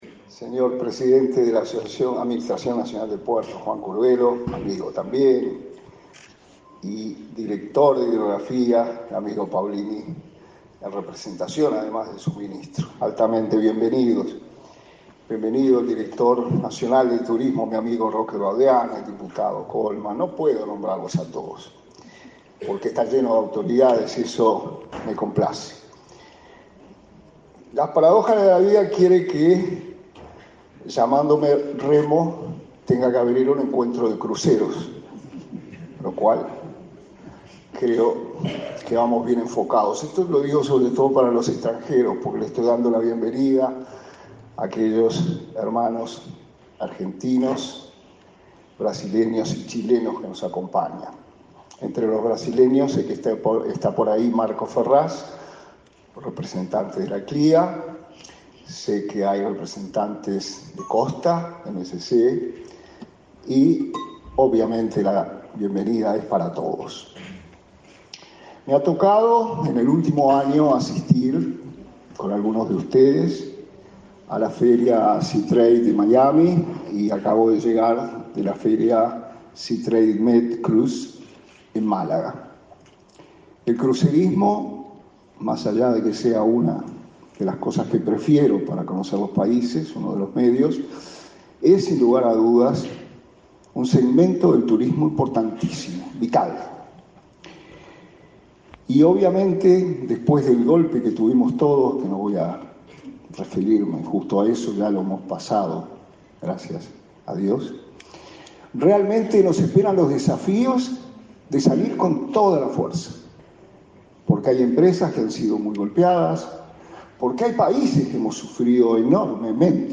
Conferencia de prensa por el lanzamiento del VIII Encuentro Regional de Cruceros y Turismo Náutico Fluvial
Conferencia de prensa por el lanzamiento del VIII Encuentro Regional de Cruceros y Turismo Náutico Fluvial 13/10/2022 Compartir Facebook X Copiar enlace WhatsApp LinkedIn Este 13 de octubre se lanzó el VIII Encuentro Regional de Cruceros y Turismo Náutico Fluvial, en Colonia del Sacramento. Participaron el ministro interino de Turismo, Remo Monzeglio, y el presidente de la Administración de Puertos, Juan Curbelo.